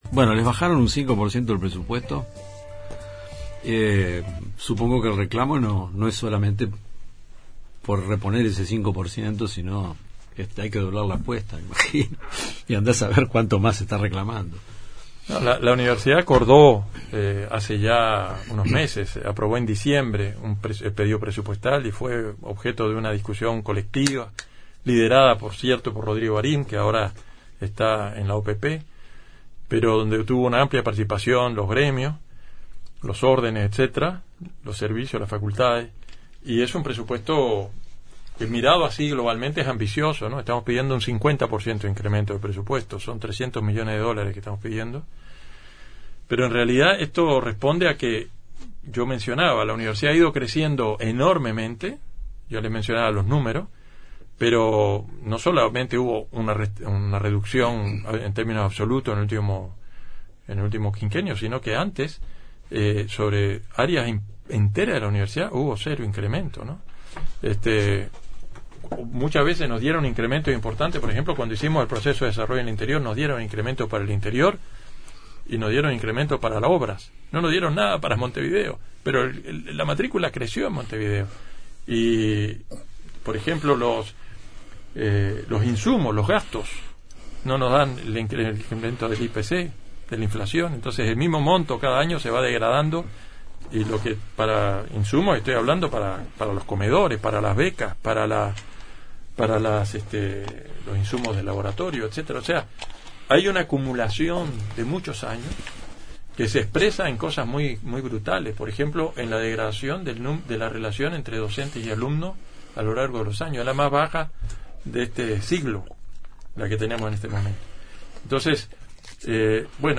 Ciclo de entrevistas con los candidatos